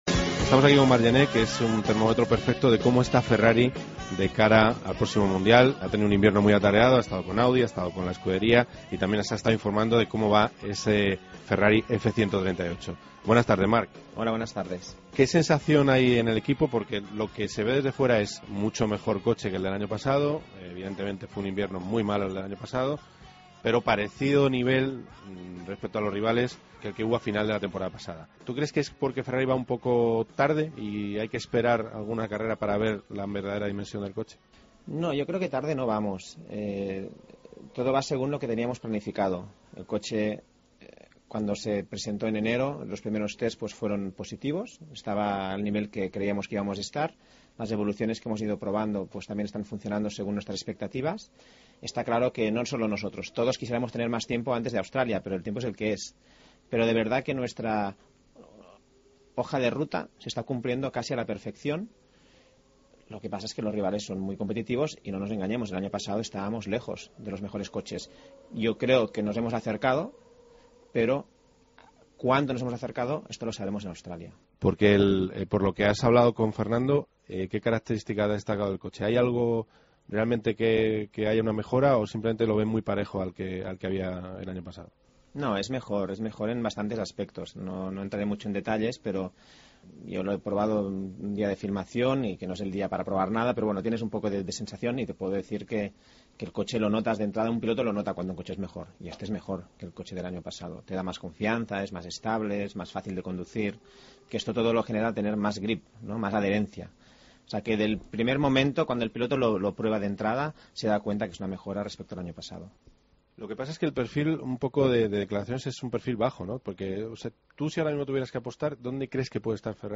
Entrevista a Marc Gené, piloto probador de Ferrari